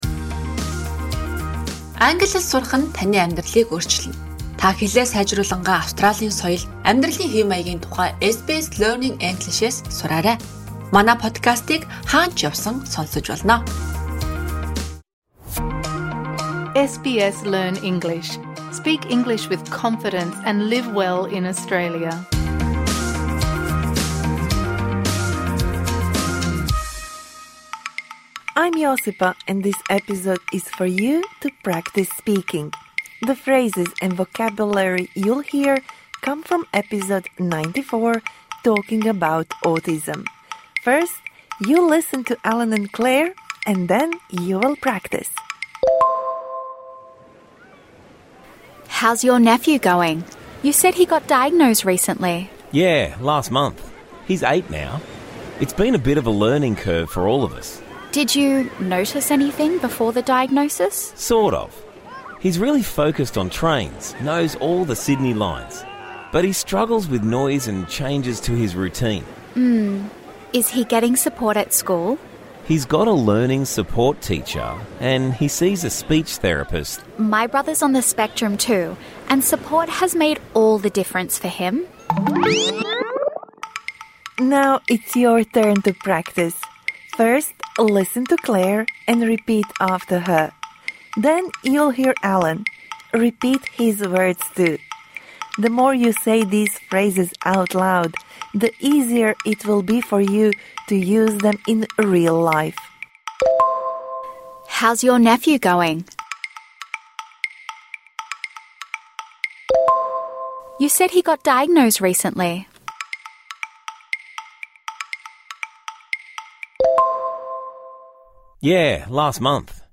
This bonus episode provides interactive speaking practice for the words and phrases you learnt in #94 Talking about autism (Med).